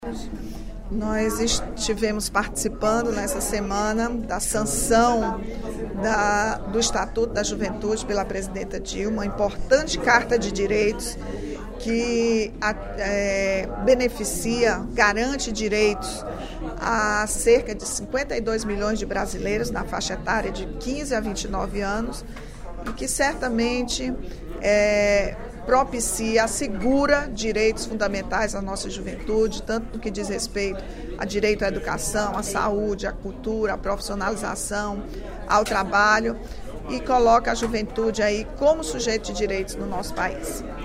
A sanção do Estatuto da Juventude pela presidente da República, Dilma Rousseff, foi comemorada pela deputada Rachel Marques (PT) na sessão plenária da Assembleia Legislativa desta quinta-feira (08/08).